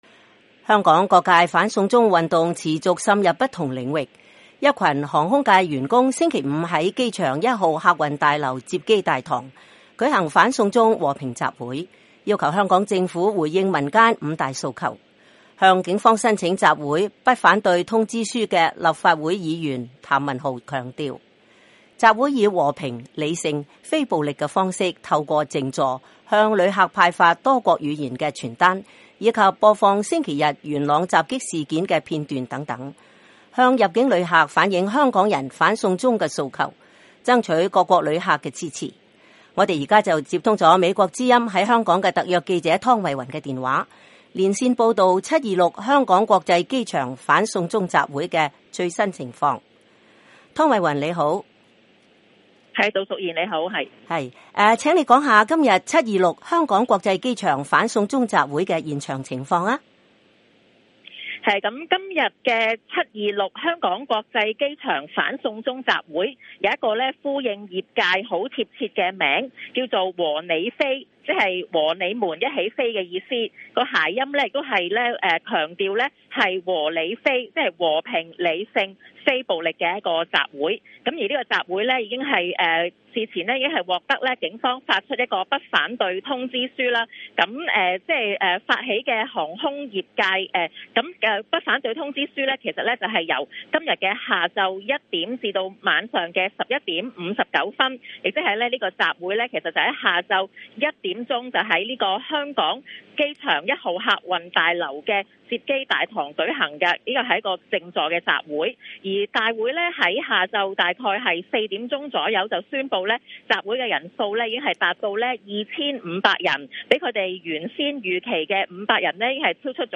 香港機場反送中集會現場報道